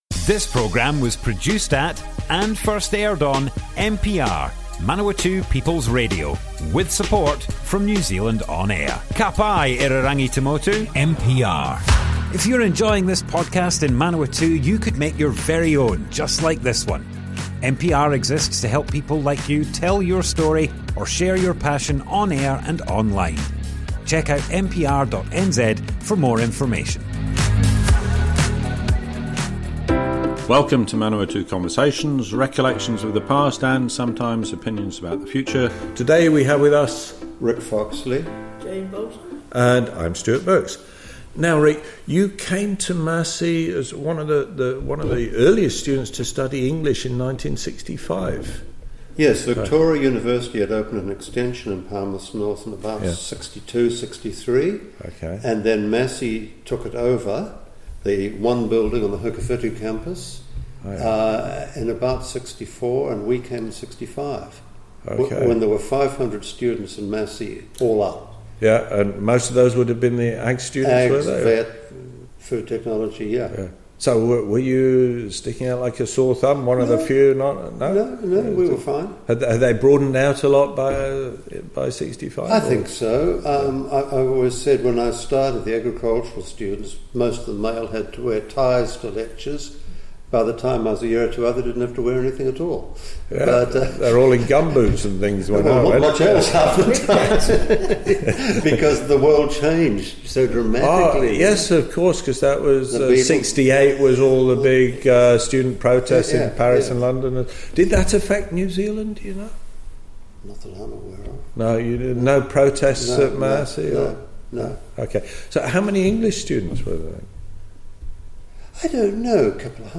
Manawatu Conversations More Info → Description Broadcast on Manawatu People's Radio, 8th August 2023.
oral history